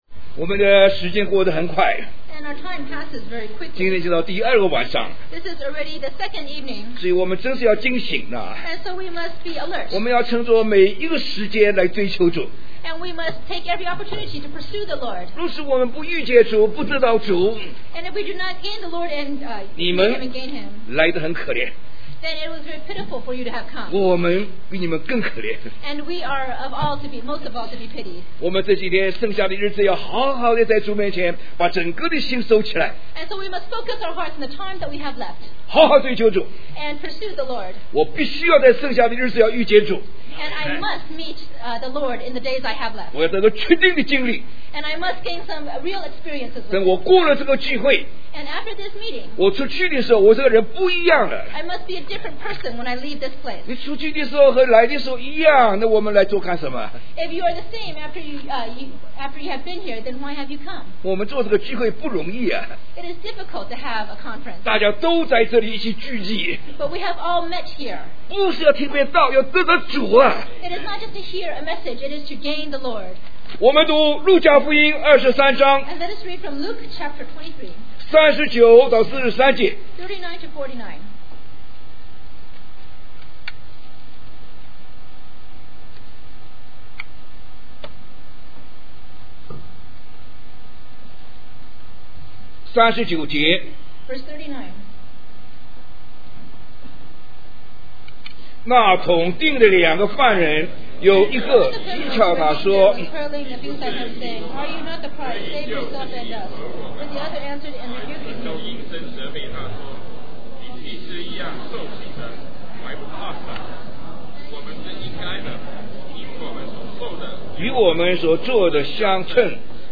In this sermon, the speaker emphasizes the importance of pursuing the Lord and gaining a real experience with Him. The speaker urges the audience to focus their hearts on the time they have left and not waste the opportunity to meet and gain the Lord. The speaker also highlights the purpose of the conference, which is not just to hear a message, but to gain the Lord.